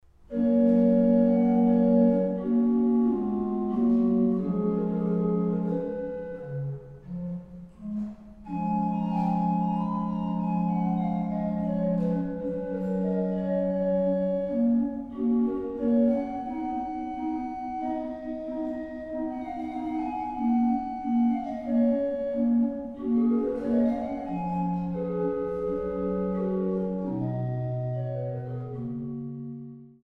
Kloß-Orgel der Kirche St. Magdalenen Langenbogen